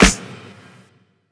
CLAPSNRW.wav